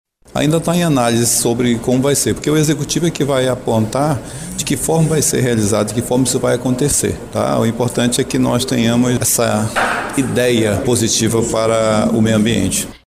O autor do projeto de lei (PL), vereador Kennedy Marques, do PMN, explica que os critérios para que a autorização seja concedida serão definidos pelo poder executivo.
Sonora-1-Kennedy-Marques-–-vereador.mp3